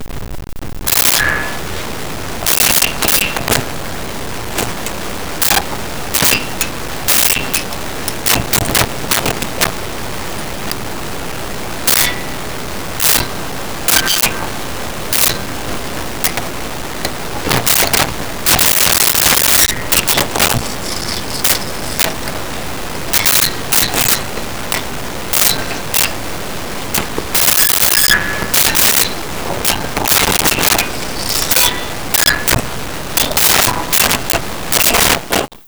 Glass Clinks And Motion 01
Glass Clinks And Motion 01.wav